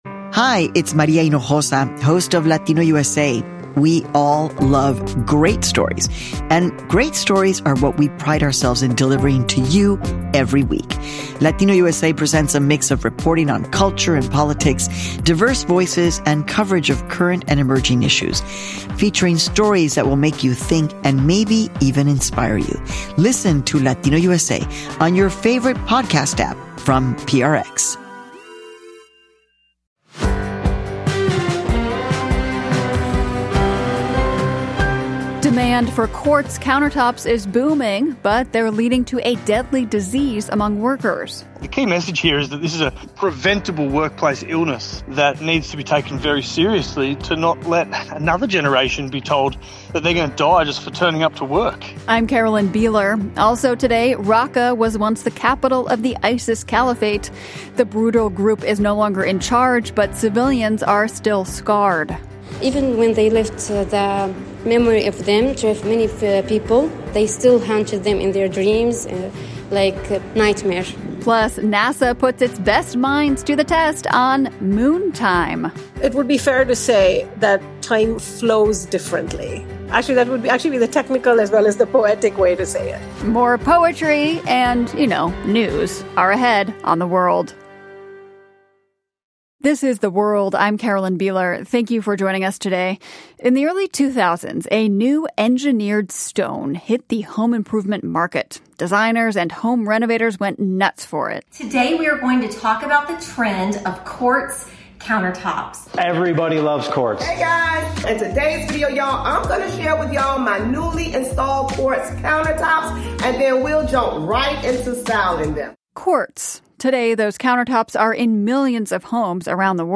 But the silica dust produced during mining and manufacturing can make it deadly. We hear from Turkish miners about their experiences.